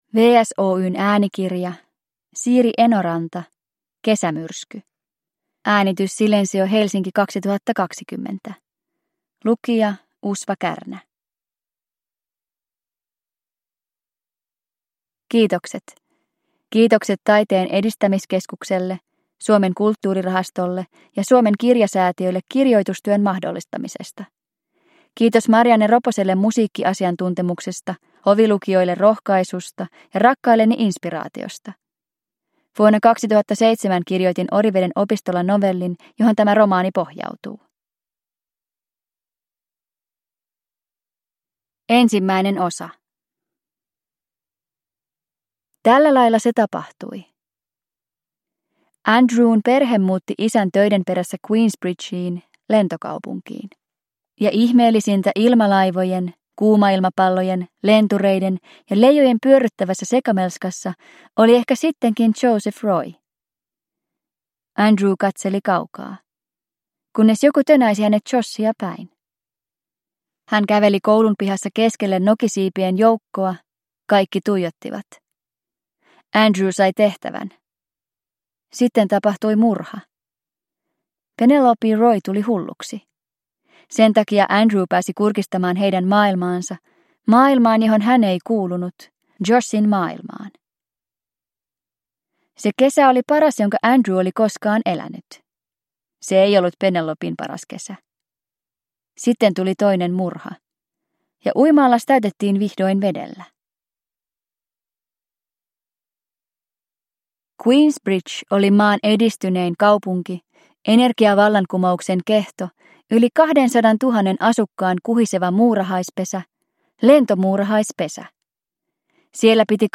Kesämyrsky – Ljudbok – Laddas ner